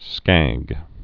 (skăg)